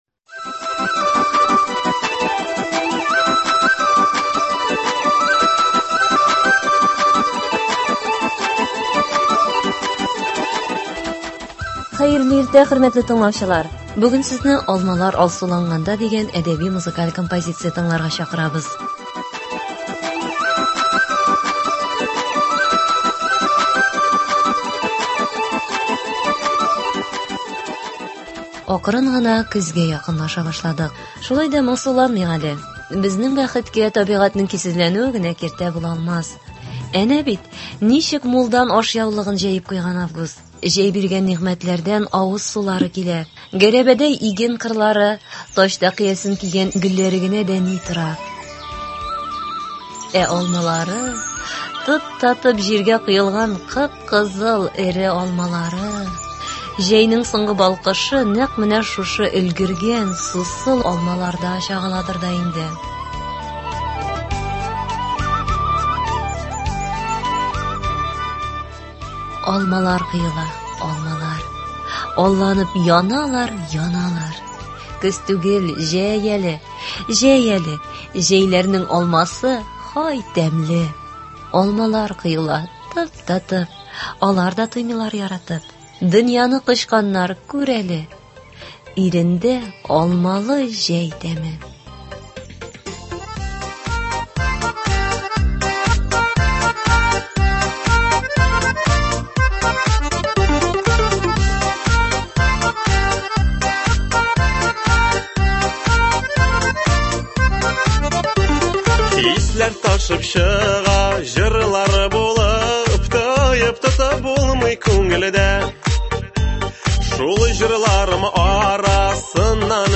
Әдәби-музыкаль композиция. 1 август.